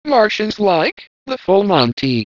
takeoff.wav